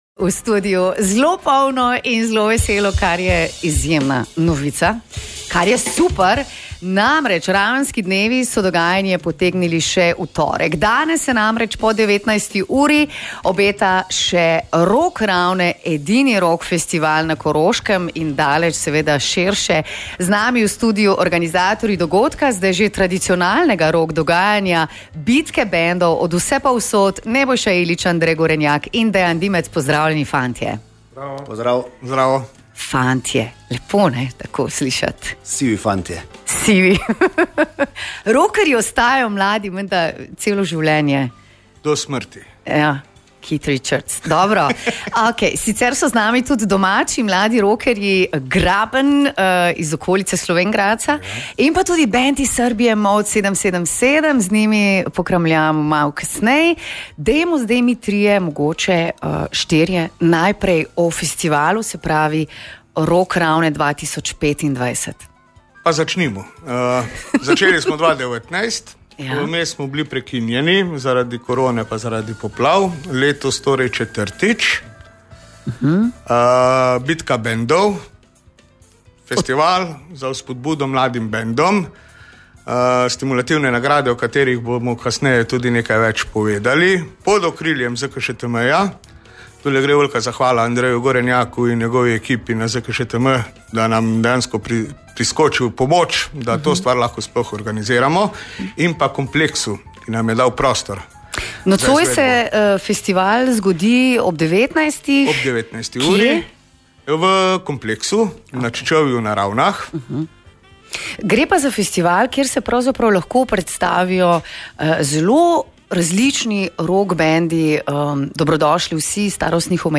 O festivalu ter sporočilu rocka smo govorili.